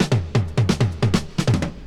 Cocaine Fill.wav